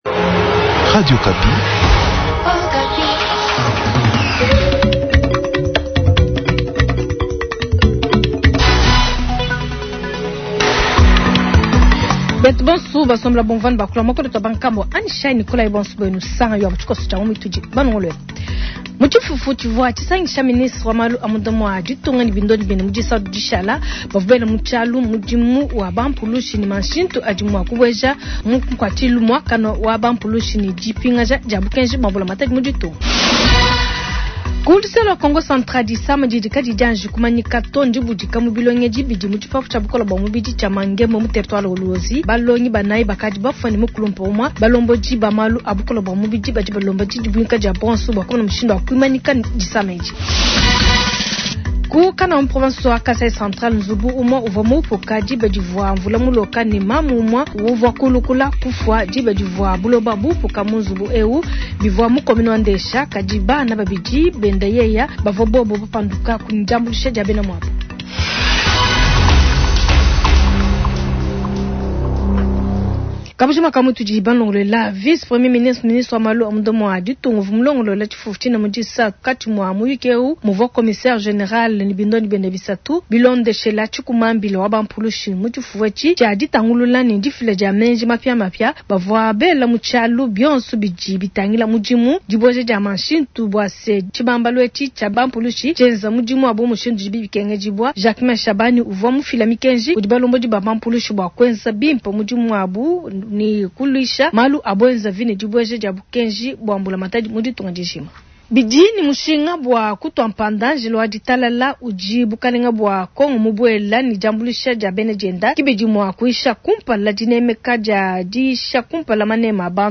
Journal matin
Ngumu ya mu ditanu dia  matuku 120226